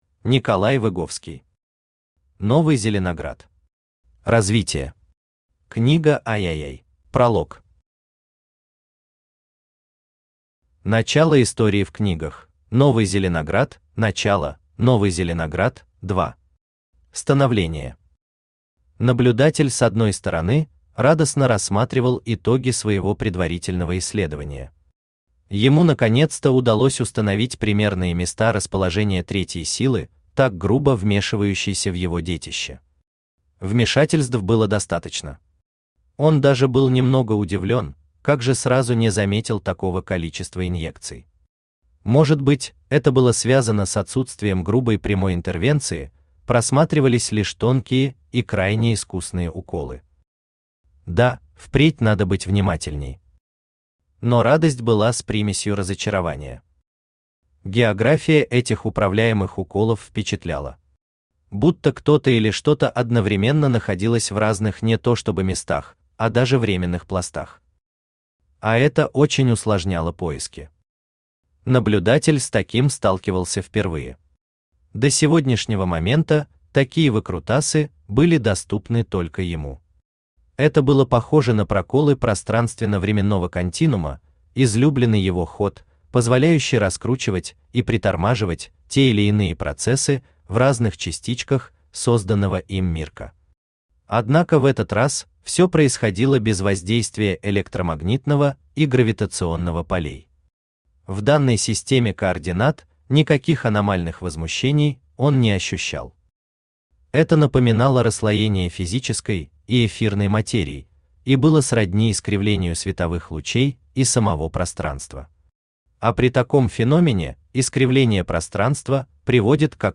Аудиокнига Новый Зеленоград. Развитие. Книга III | Библиотека аудиокниг
Книга III Автор Николай Выговский Читает аудиокнигу Авточтец ЛитРес.